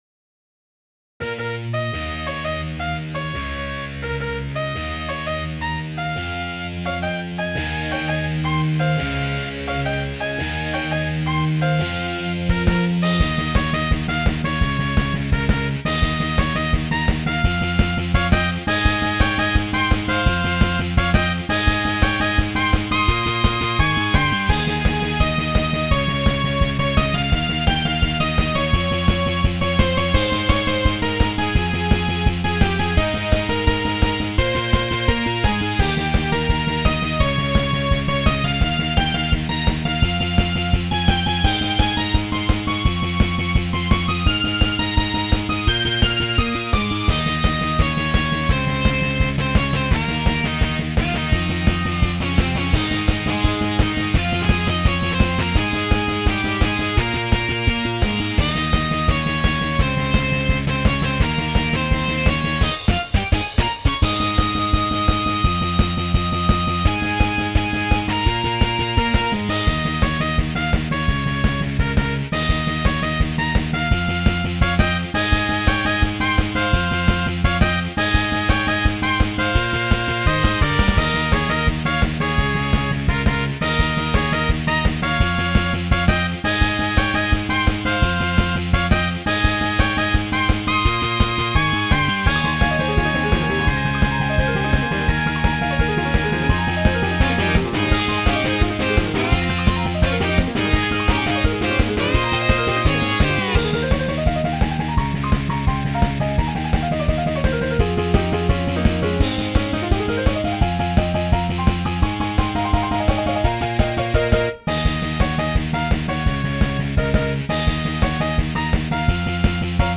ジャンル Pop
説明 落ち着いていて、元気な曲です。